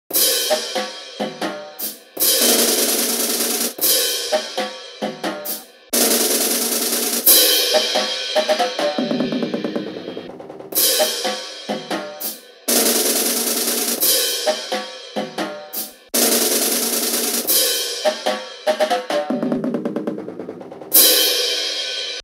wolf drums.wav